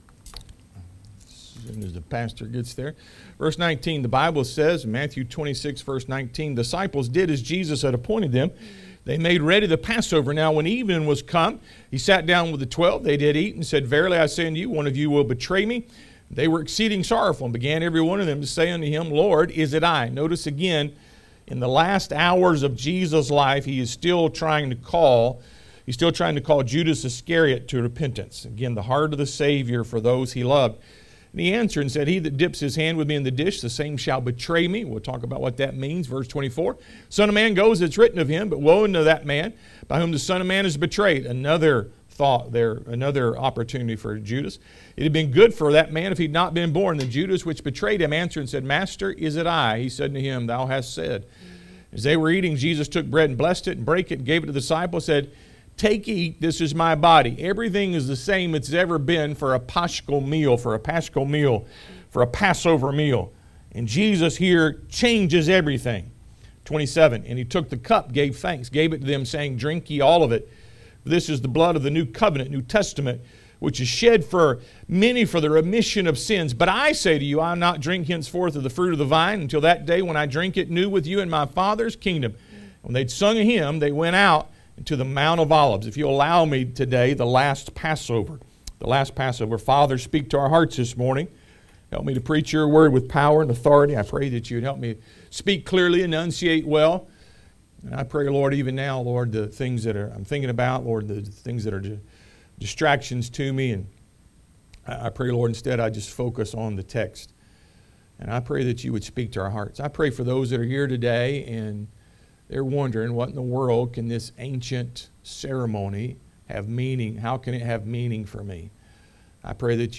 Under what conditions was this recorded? Matthew 26:19–30 Service Type: Sunday AM Road to Calvary The Last Passover Matthew 26:19–30 1.